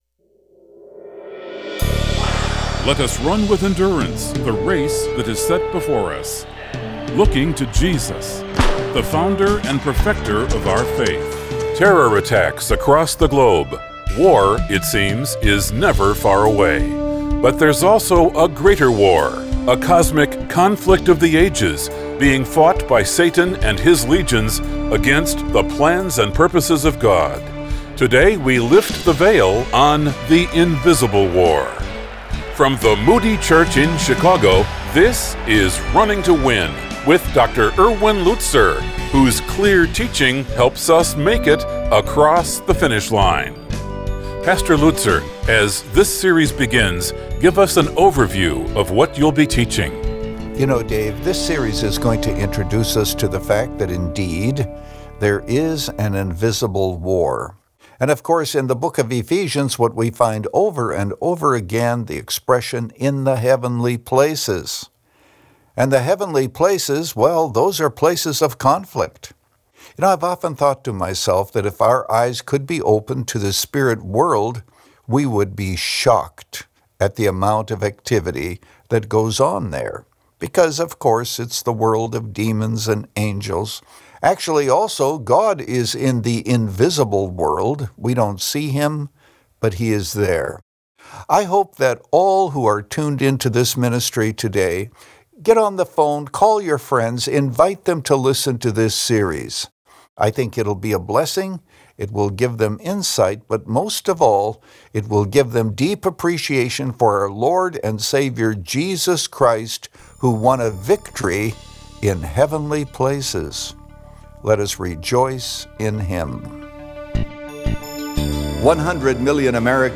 Since 2011, this 25-minute program has provided a Godward focus and features listeners’ questions.